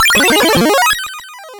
SFX
updated some sound effects to be quieter